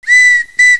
toot.wav